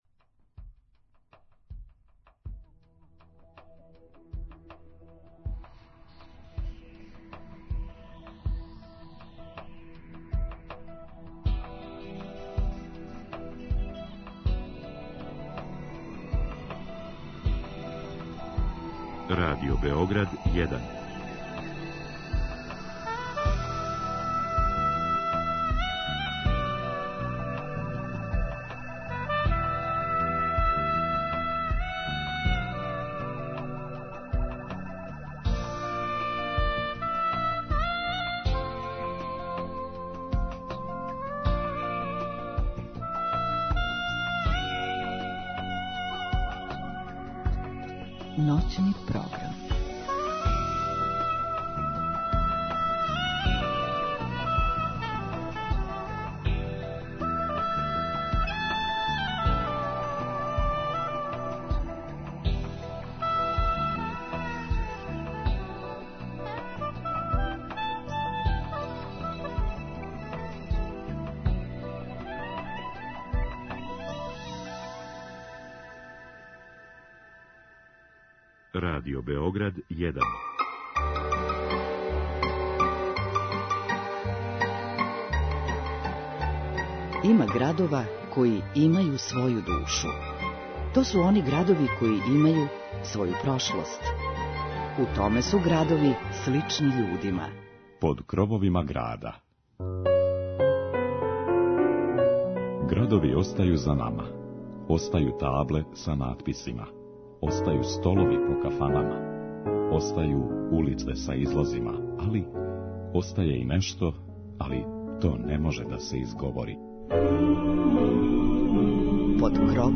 У ноћном програму слушаћете популарне певаче са простора Косова и Метохије.